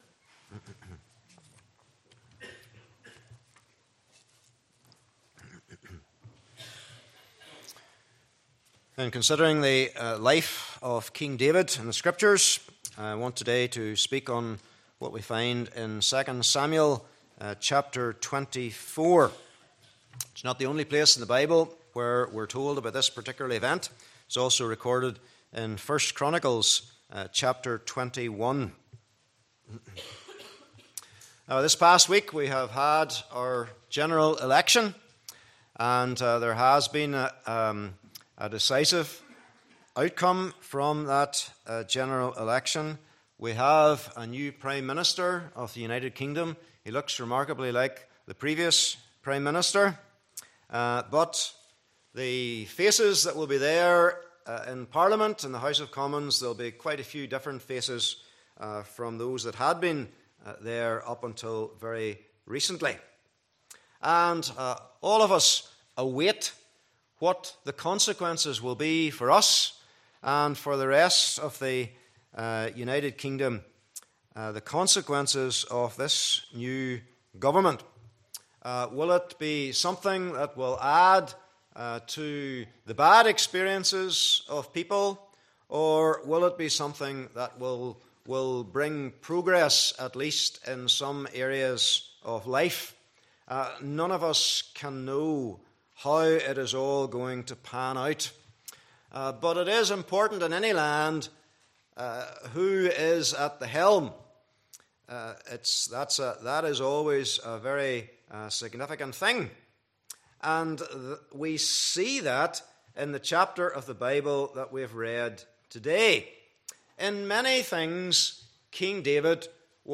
Passage: 2 Samuel 24:1-25 Service Type: Morning Service